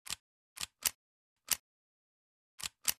reload_ak47.ogg